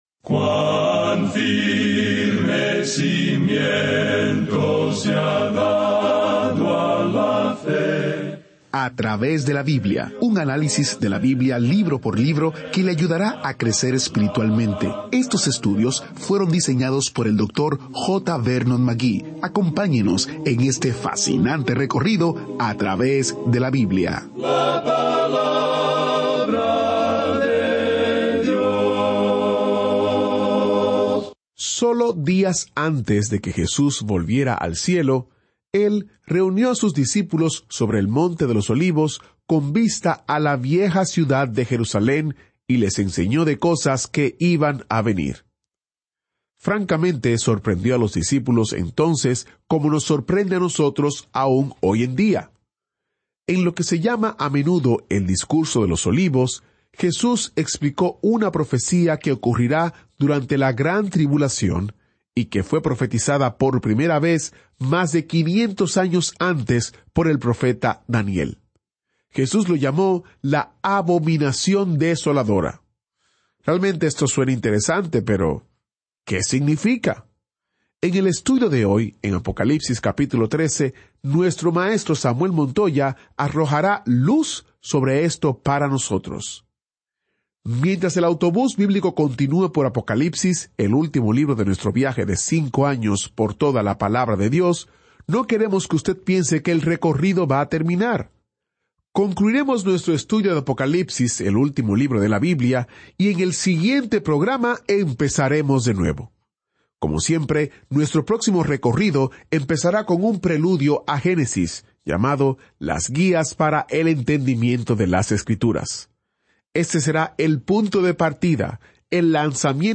Este es un programa de radio diario de 30 minutos que sistemáticamente lleva al oyente a través de toda la Biblia.